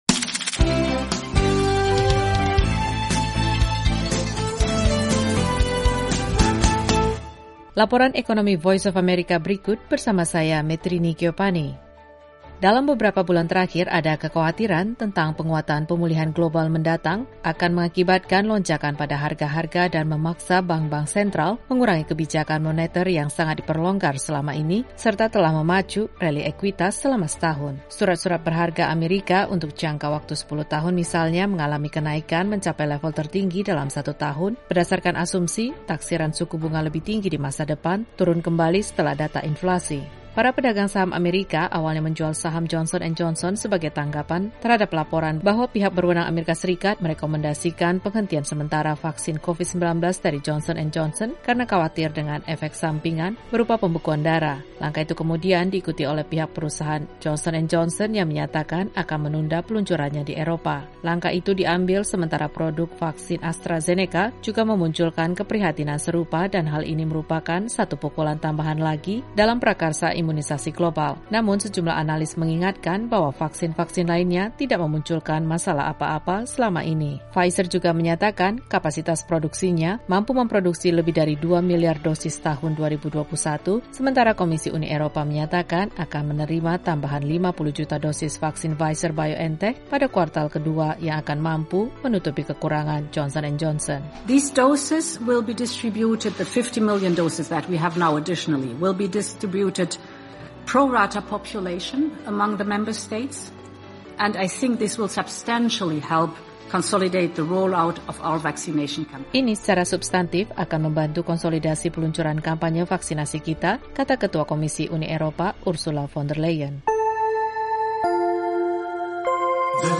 Laporan Ekonomi dan Bisnis VOA tentang Uni Eropa yang menaruh harapan pada vaksin Pfizer.